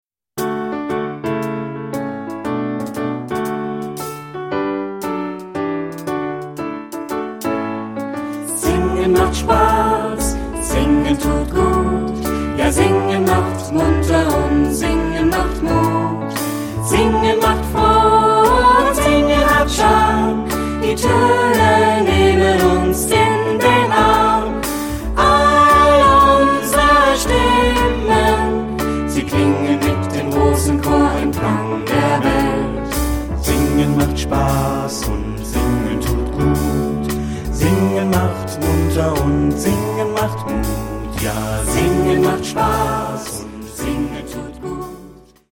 Ad libitum (3 Ad libitum Stimmen).
Kanon. Choraljazz.
jazzy ; fröhlich ; rhythmisch
Tonart(en): D-Dur